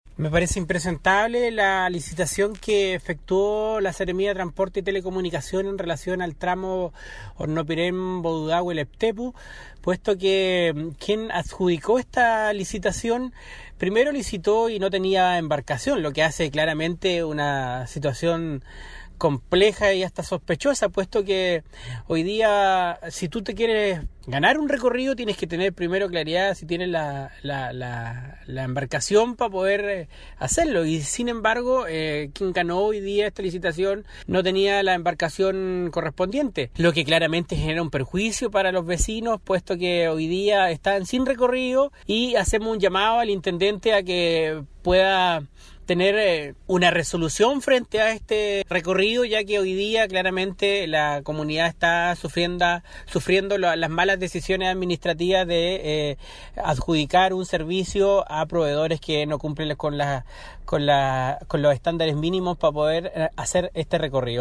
Roberto Soto, Consejero Regional de Los Lagos, se mostró muy molesto por esta situación que afecta a los vecinos de la isla Llancahué.